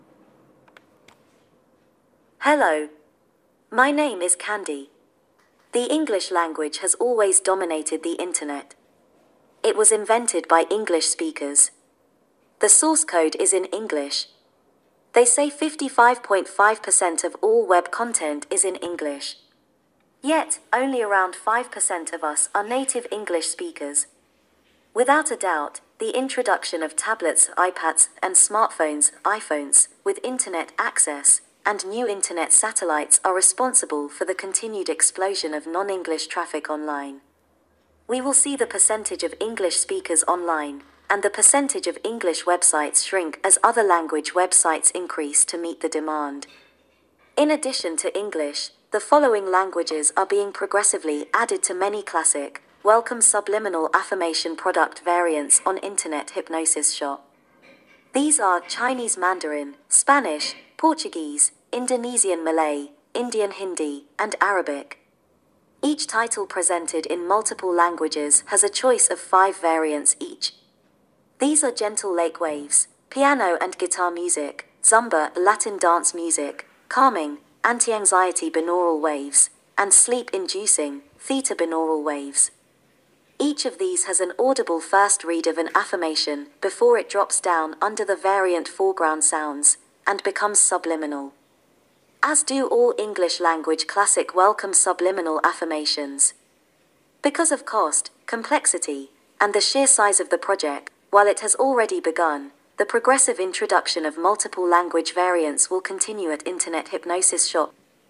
our hostess